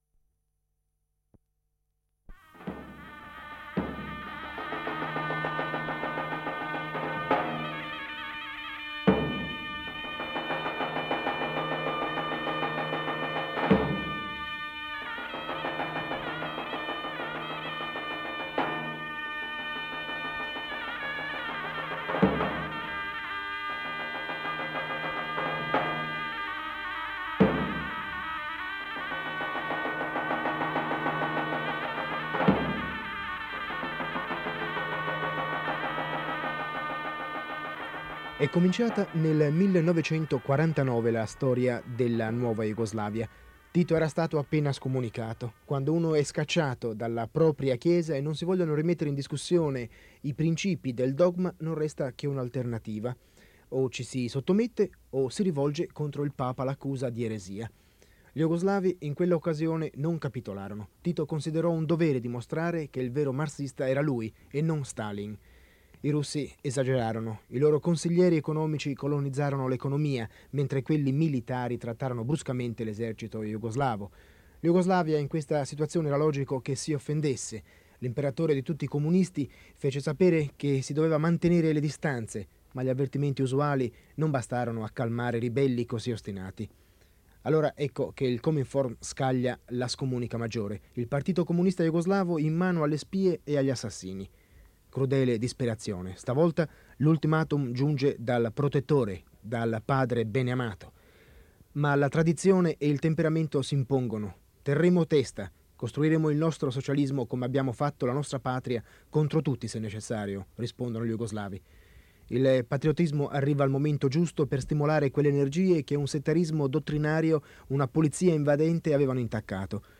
A Belgrado, un ingegnere racconta l’attuale situazione industriale del Paese, soffermandosi sugli sviluppi avviati da Tito dopo la rottura con l’Unione Sovietica nel 1948. Al centro dell’analisi, il sistema di autogestione dei lavoratori, tratto distintivo del modello jugoslavo. Nella seconda parte della puntata, l’attenzione si sposta sulla letteratura nazionale e sul ruolo nel raccontare una società in profonda trasformazione.